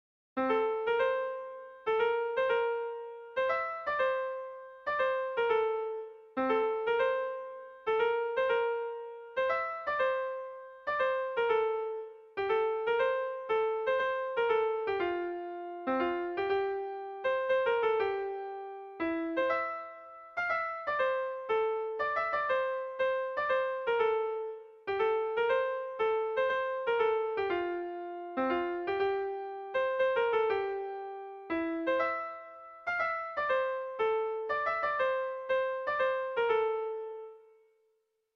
Irrizkoa
ABDEB